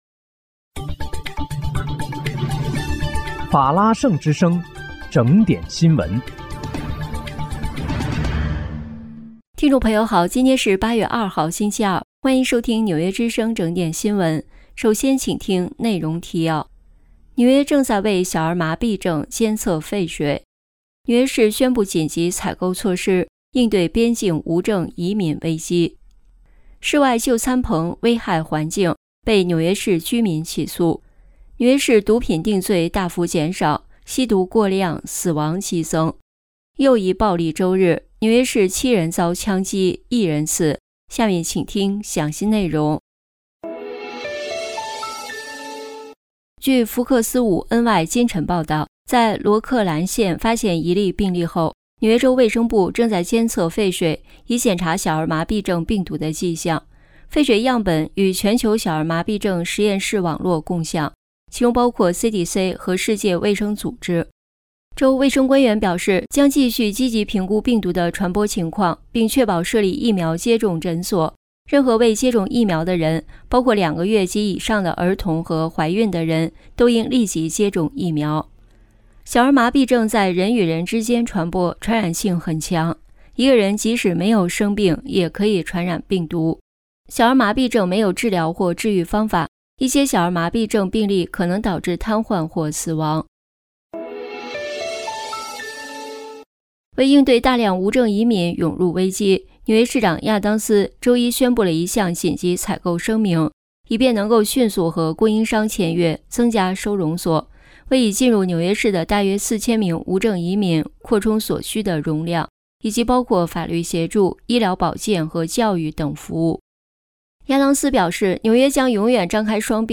8月2日（星期二）纽约整点新闻